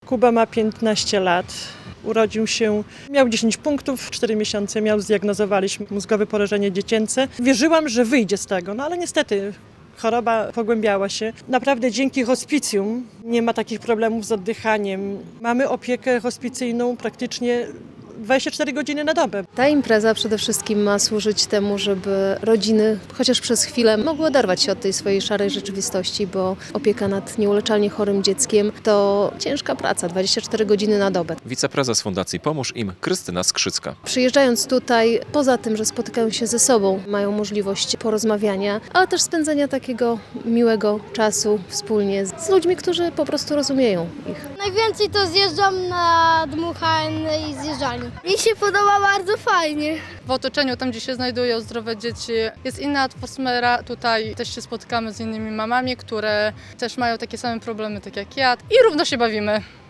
Podopieczni białostockiej Fundacji "Pomóż Im" wzięli udział w rodzinnym pikniku w Supraślu - relacja